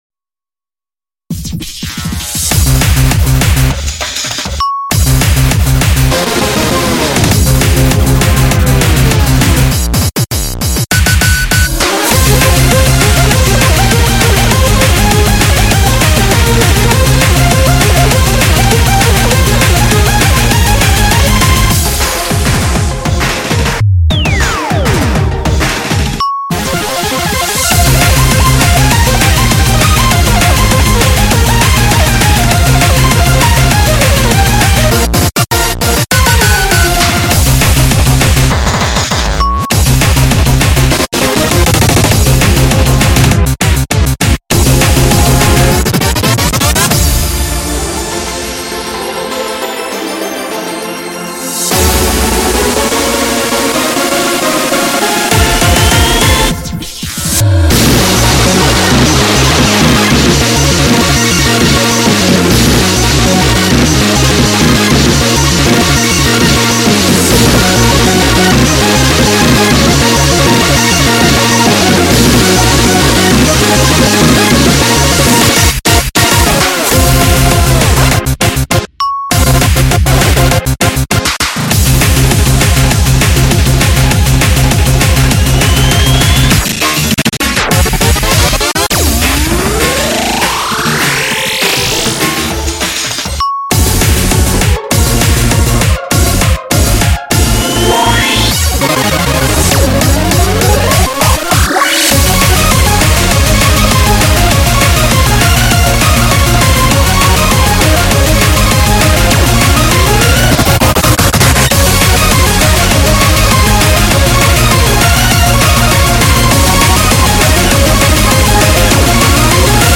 BPM200
Audio QualityPerfect (High Quality)
Comments[Emotional Hardcore Techno]